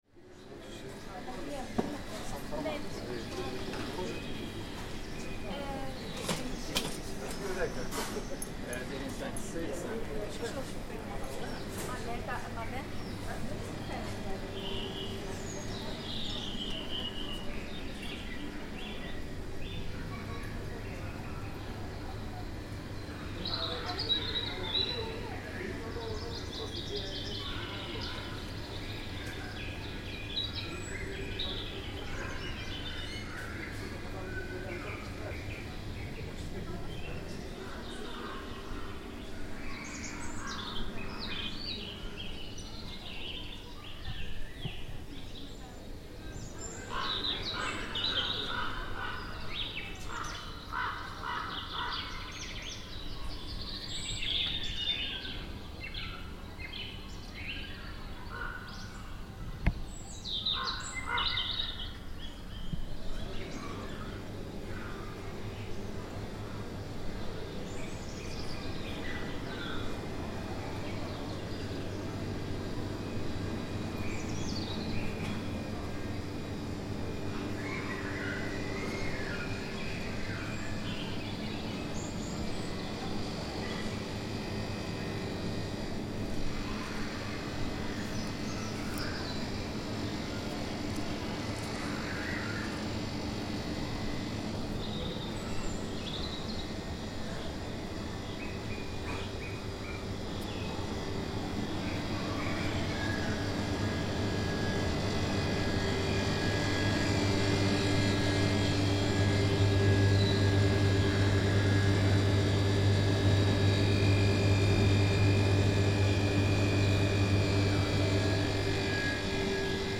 At Tallinn airport terminal, with birdsong piped into a souvenir gift shop, the children's play area, a game of ping pong in progress and the chatter of passing passengers in various languages.